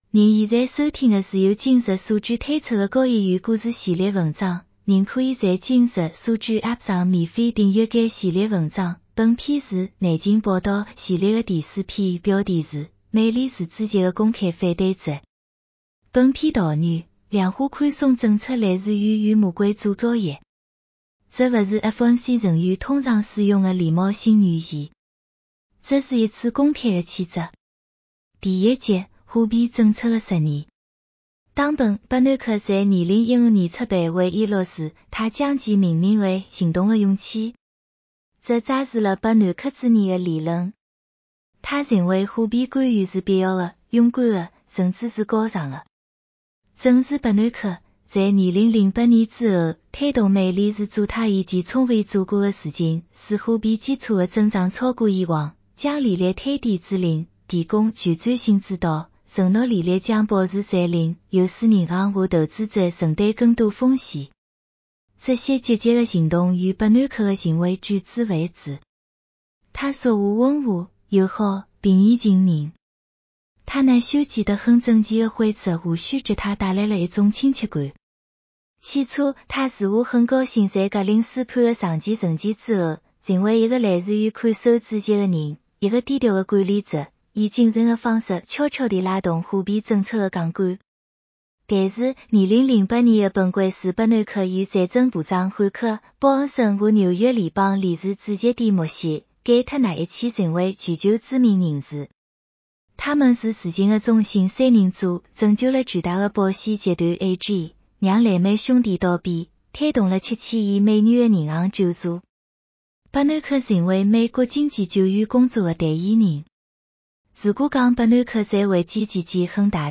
美联储主席的公开反对者 丨《内情报道》系列四 男生普通话版